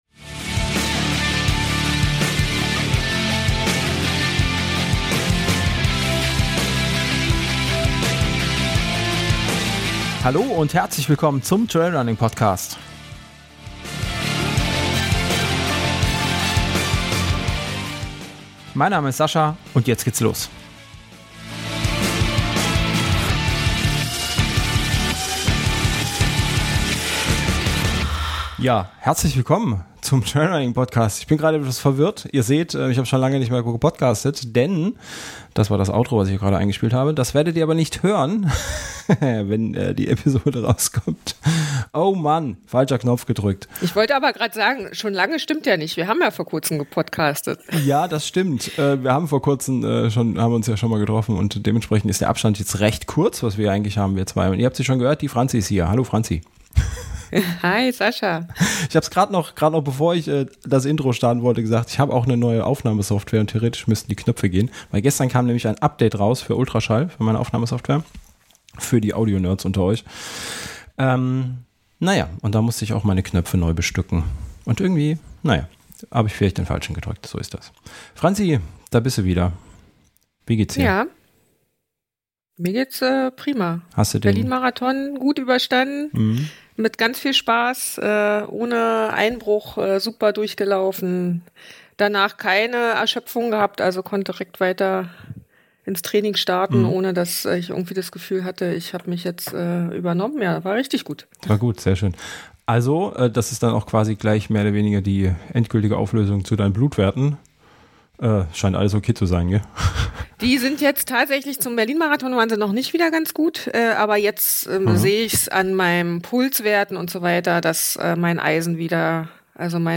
Live Call-in Show, die Fünfte